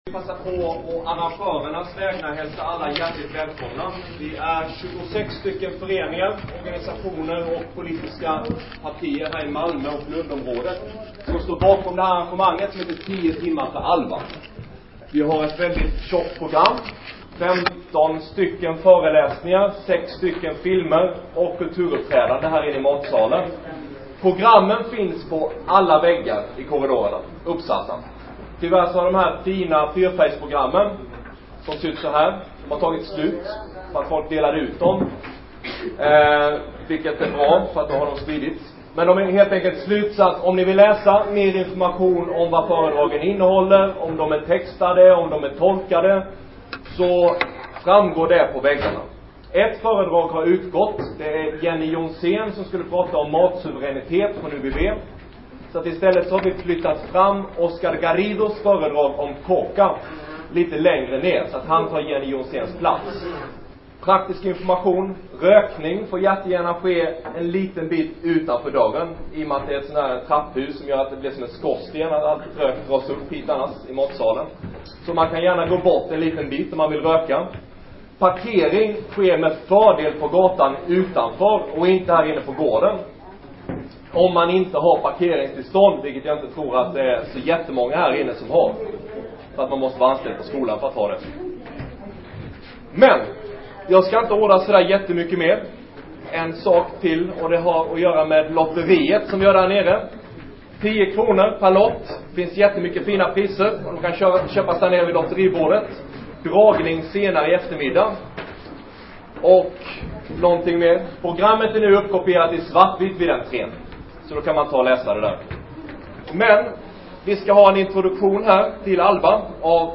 Lördagen den 6 oktober genomfördes ett solidaritetsarrangemang i Malmö som hette ”Tio timmar för ALBA”.